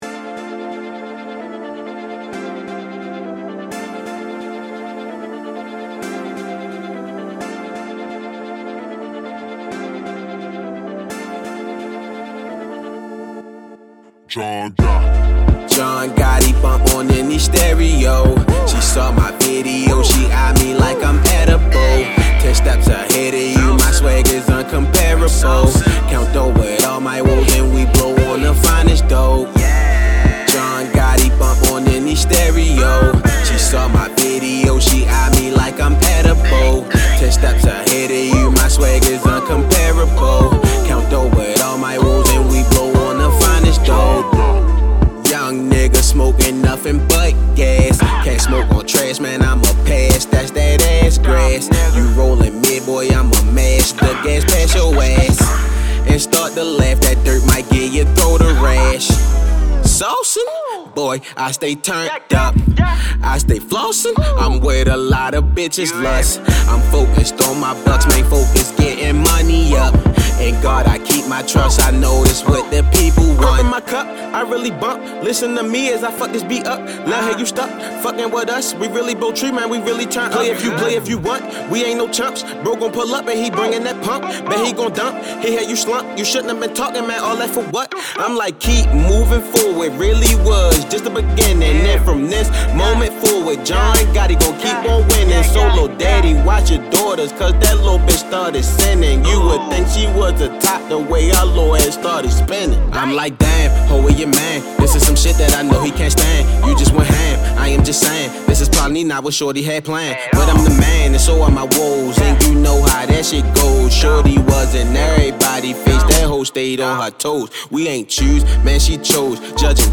Hiphop
Description : Cool wavy music.